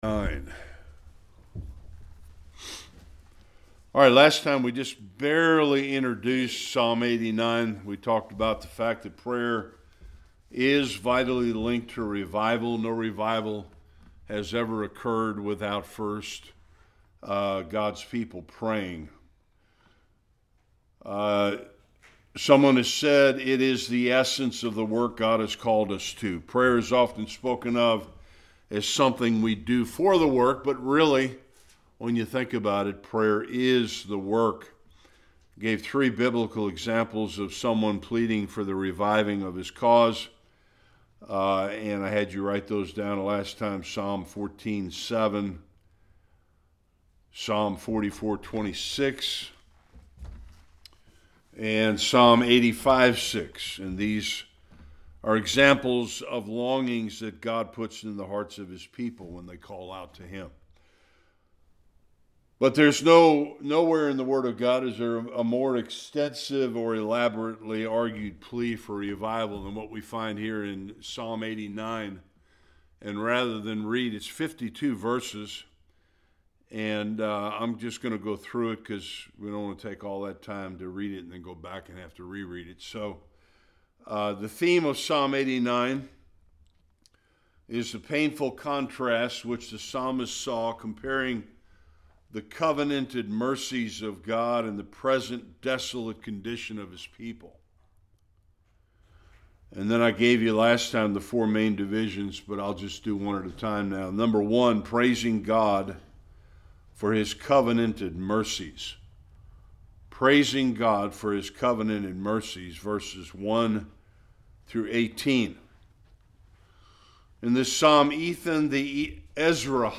Psalm 89 Service Type: Bible Study A plea for revival in Psalm 89.